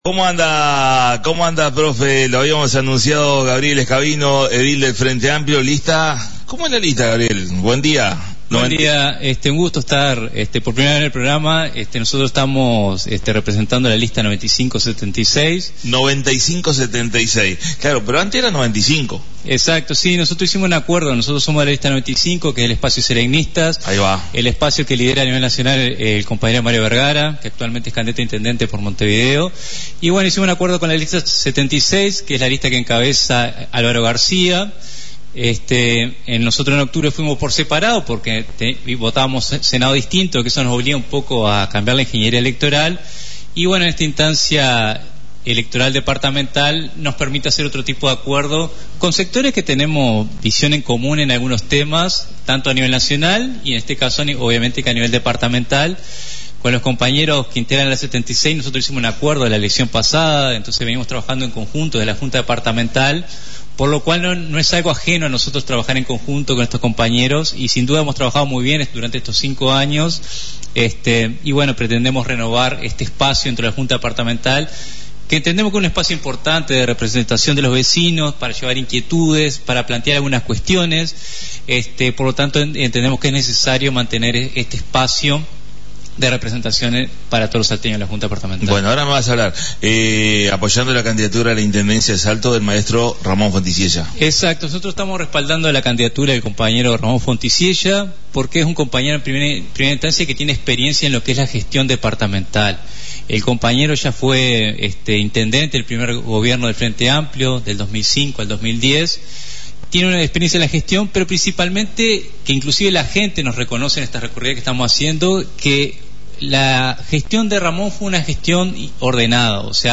La visita a la Radio del Profesor y Edil del Frente Amplio Gabriel Scabino Lista 9576 y apoyando la Candidatura a la Intendencia de Salto del Maestro Ramón Fonticiella